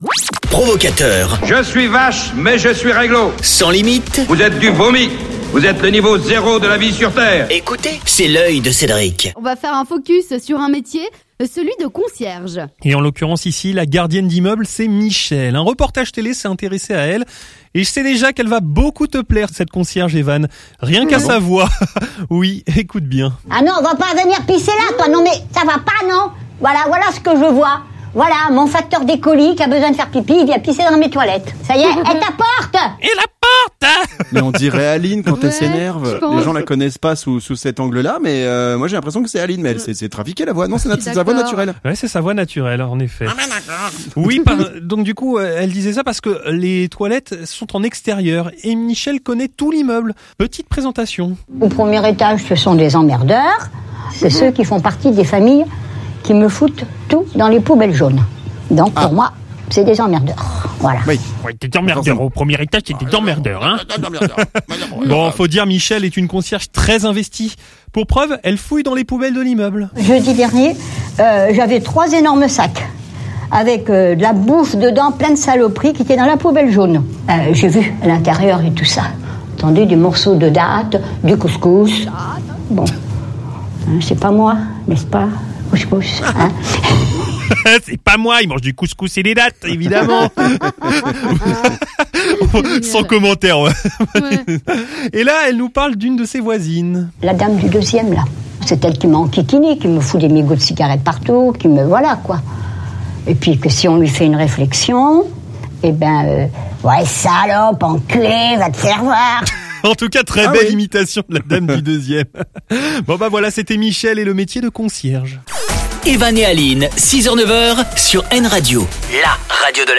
Petit reportage